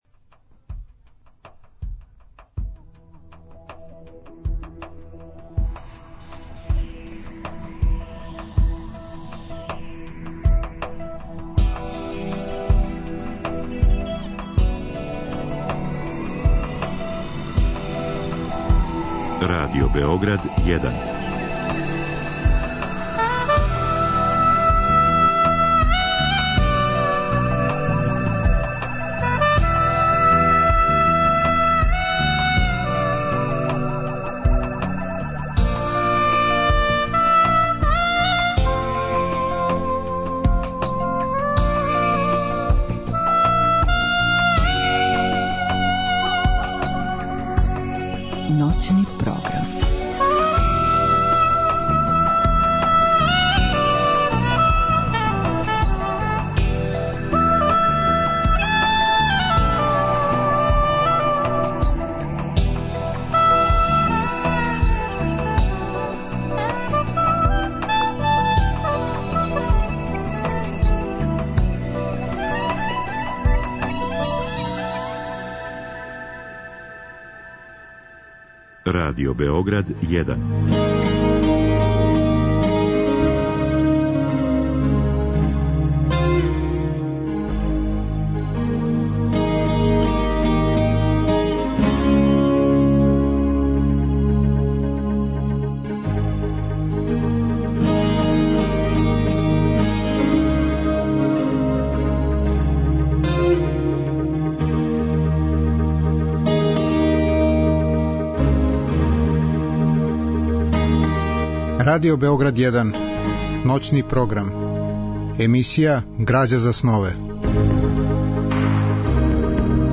Разговор и добра музика требало би да кроз ову емисију и сами постану грађа за снове.
У другом делу емисије, од два до четири часa ујутро, слушаћемо делове радио-драме Кројцерова соната која је рађена по истоименом делу Лава Николајевича Толстоја.